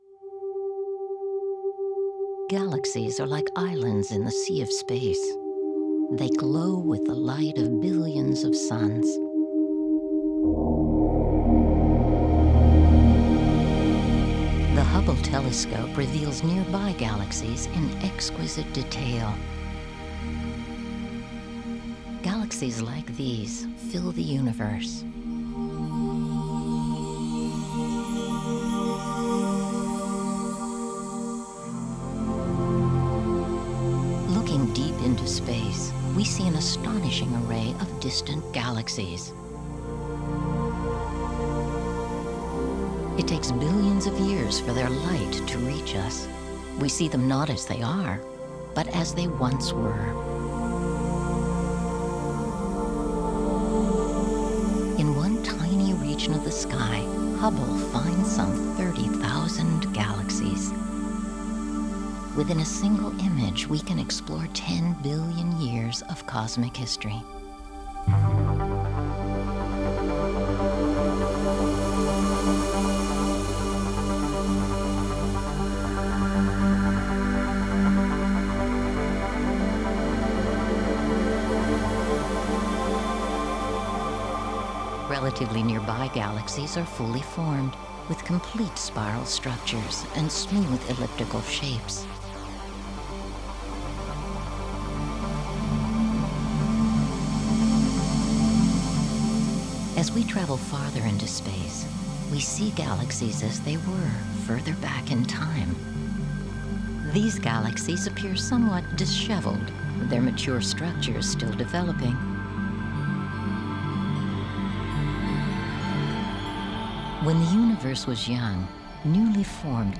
• Audio: Narration
Audio - Narration.wav